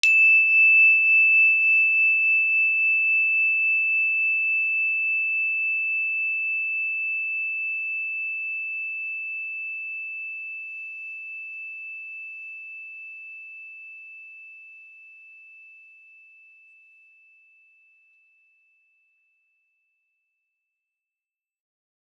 energychime_wood-E6-mf.wav